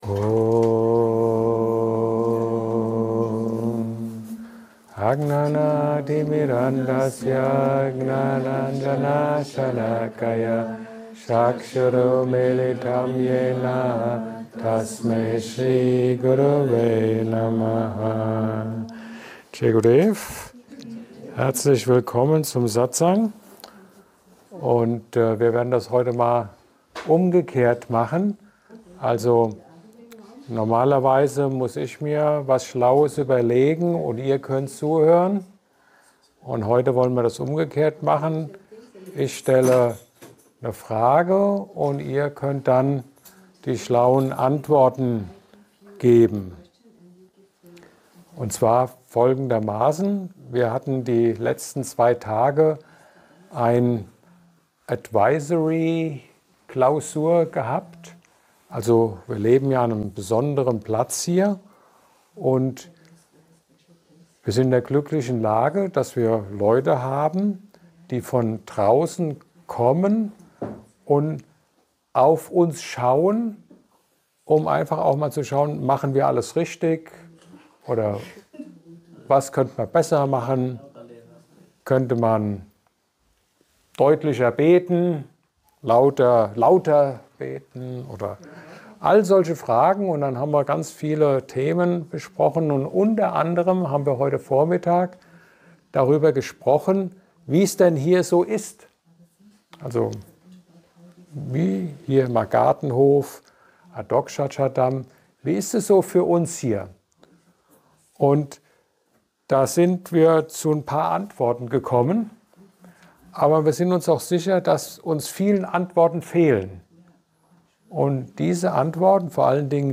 Ein Satsang